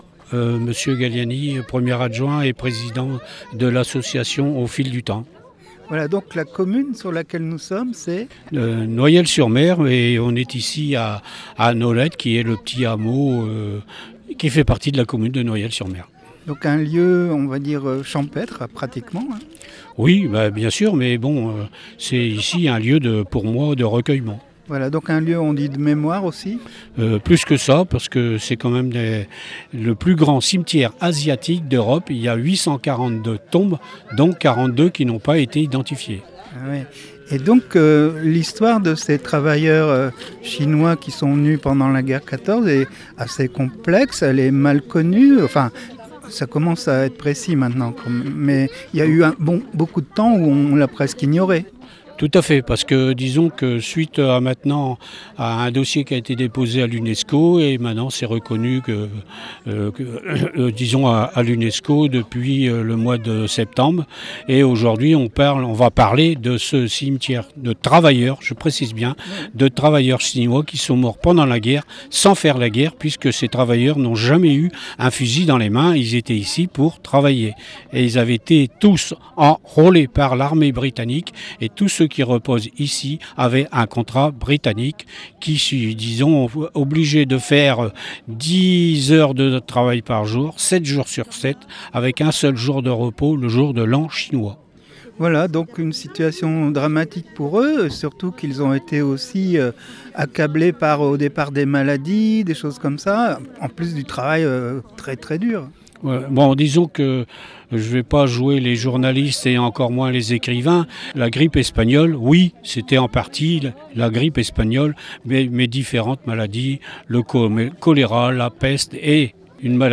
un reportage en images et en sons
Président de l'association "Au fil du temps", maire-adjoint de Noyelles-sur-mer